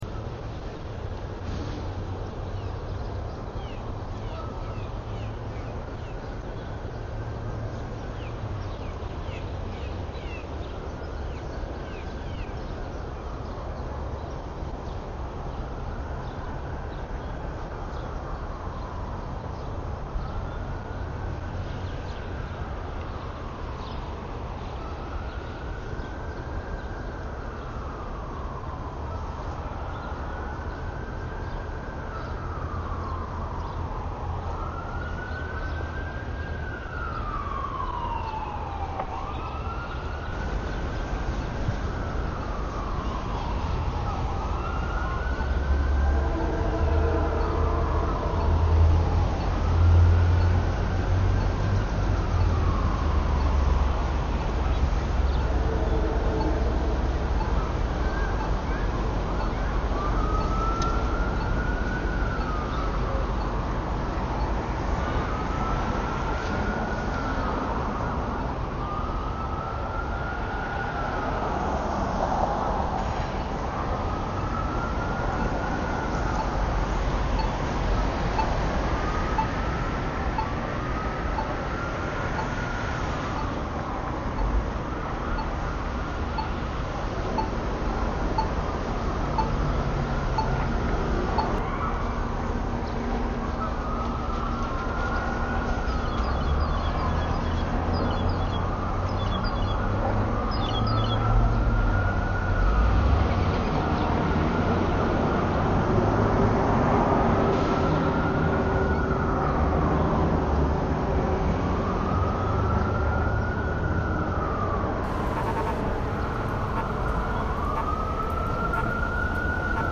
New York lockdown sound